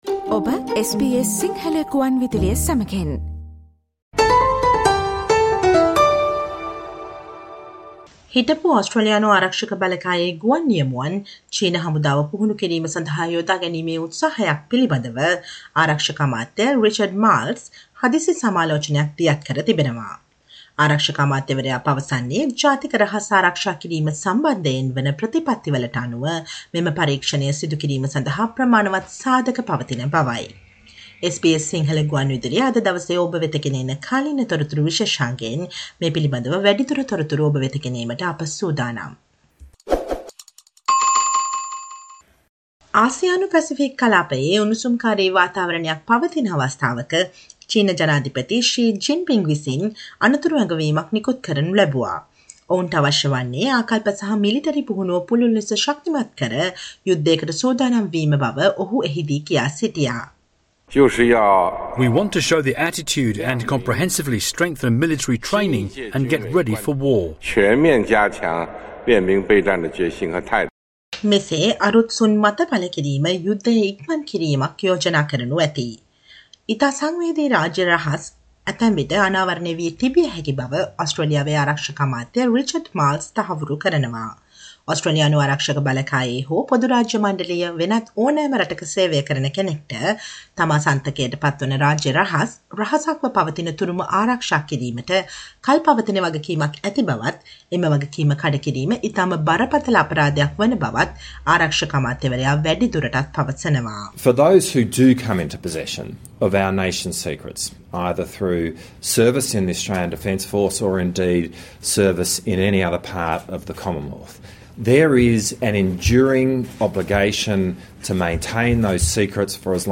Listen to the SBS Sinhala radio current affair feature on 11 Nov 2022 revealing the national security concerns as china approached a few former serving fighter pilots for their military training purposes.